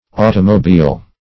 Automobile \Au"to*mo*bile`\, n. [F.]